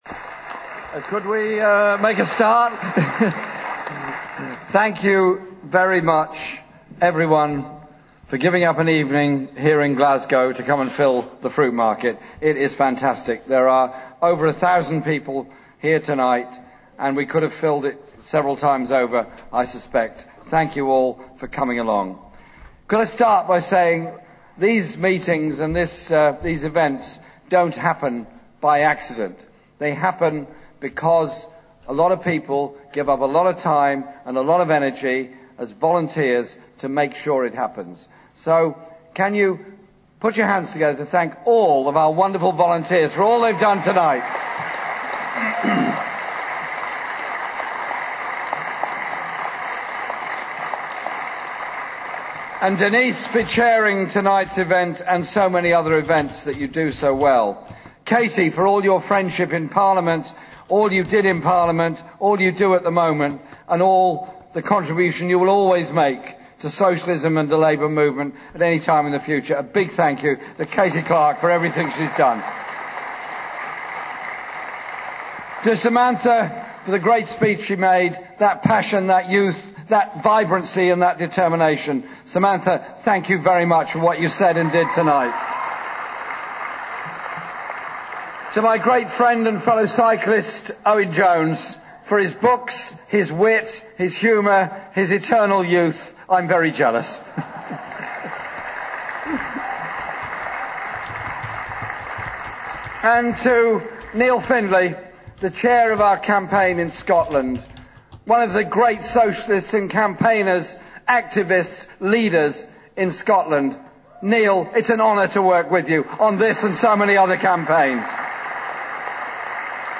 Audio from the live stream here:-
Jeremy Corbyn Glasgow speech (40m)